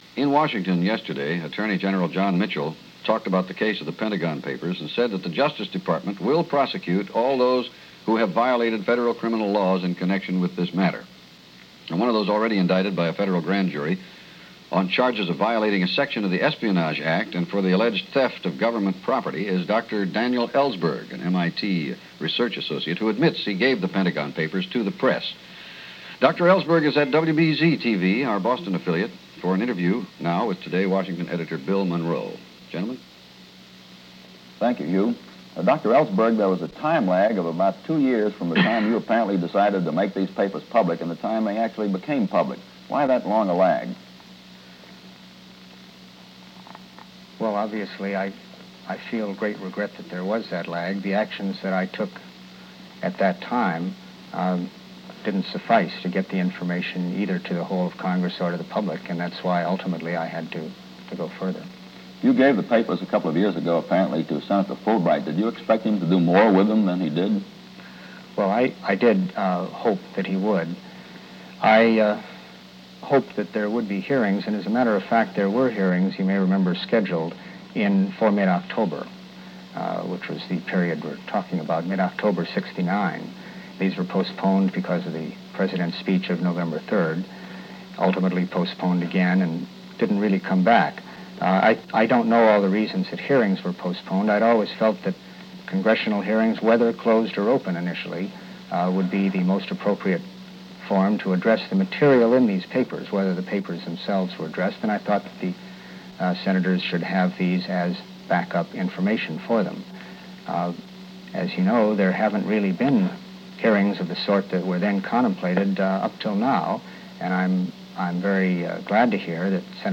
Daniel Ellsberg and The Pentagon Papers - Interview from The Today Show - July 2, 1971 - Past Daily Weekend Reference Room.
Daniel-Ellsberg-interview-July-2-1971.mp3